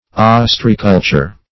Ostreaculture \Os"tre*a*cul`ture\, n. The artificial cultivation of oysters.